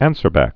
(ănsər-băk)